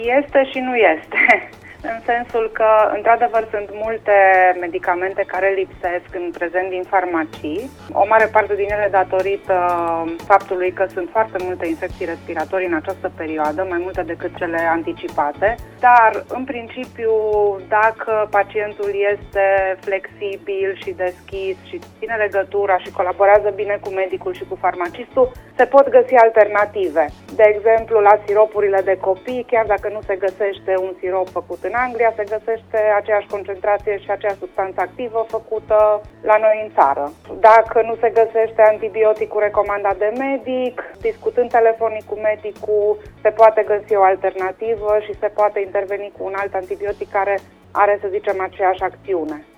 Invitată vineri, 13 ianuarie, la Radio Cluj